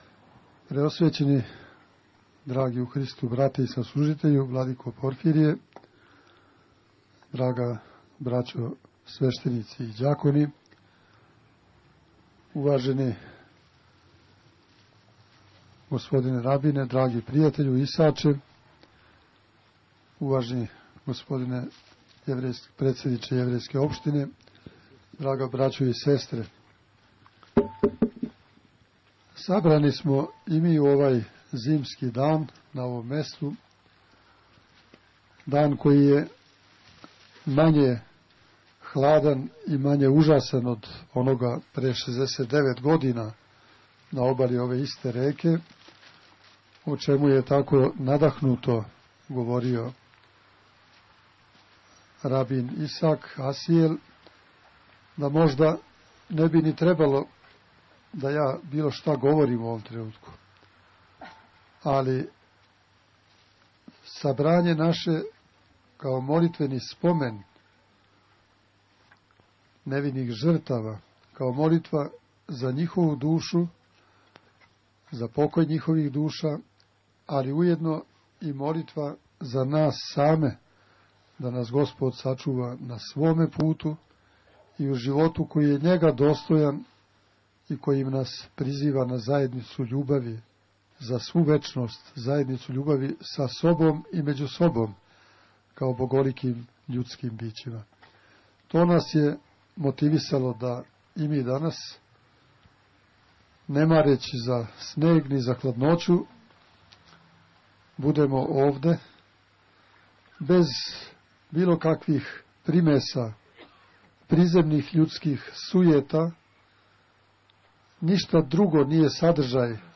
На Кеју жртава рације у Новом Саду, 23. јануара 2011. године, у организацији Православне Епархије бачке и Јеврејске општине Нови Сад, одржан је молитвени помен житељима нашег града који су невино пострадали од стране мађарских окупационих снага на данашњи дан пре 69 година.
• Беседа Епископа Иринеја: